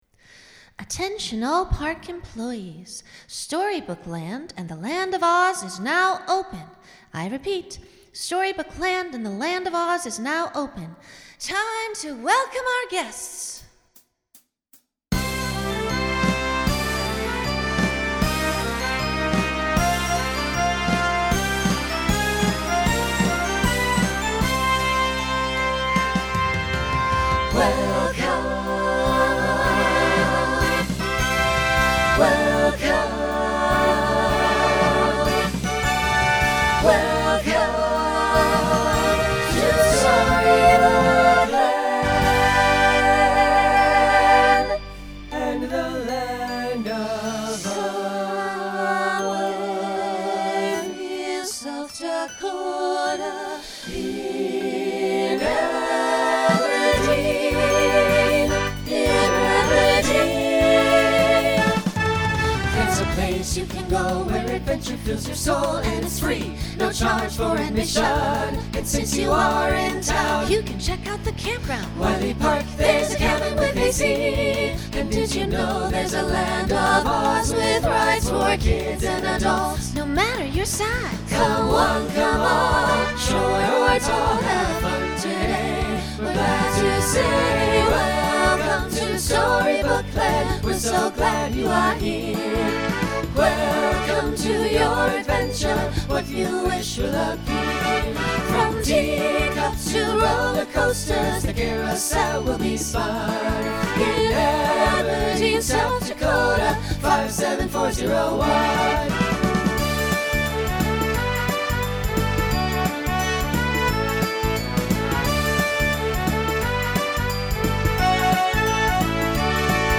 Genre Pop/Dance
Original Song Show Function Opener Voicing SATB